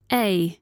Коллекция включает четкое произношение всех 26 букв, что идеально подходит для обучения детей или начинающих.
Звук буквы A в английском языке Aa ei